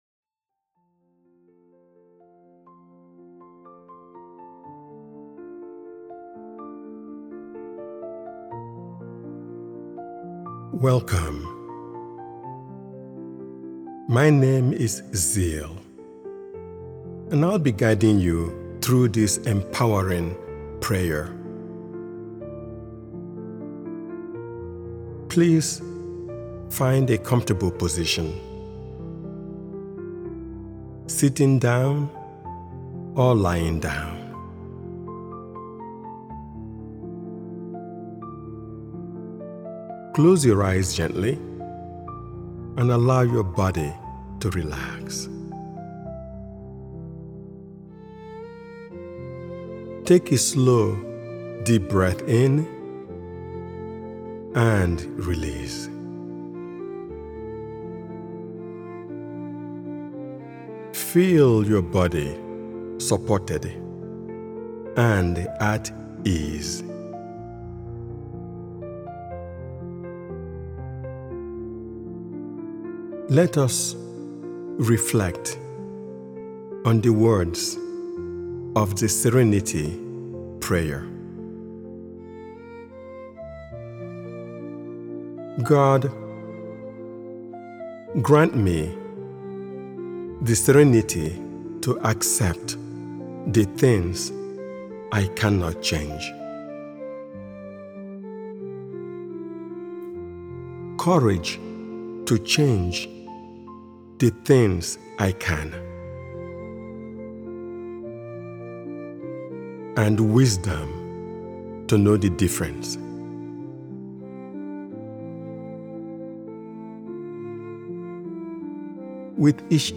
Serenity Prayer: A Guided Moment of Peace is a prayer-inspired guided meditation designed to help you release stress, cultivate inner calm, and reconnect with clarity and courage.